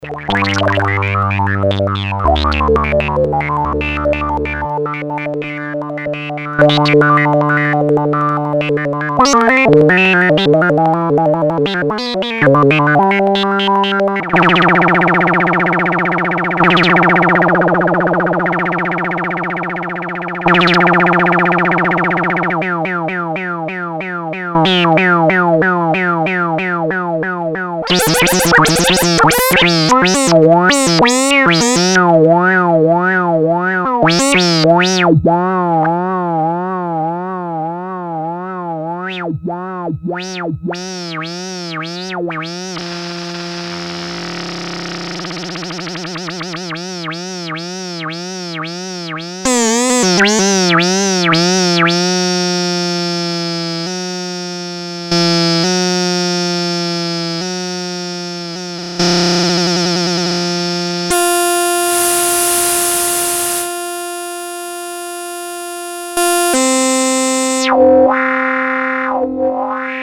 Portable analog synthesizer (but digital oscillator) similar to Electro harmonix Mini-Synthesizer or EMS synthi.
Class: Synthesizer
Polyphony: 1 note